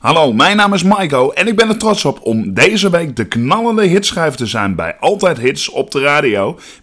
de Enschedese zanger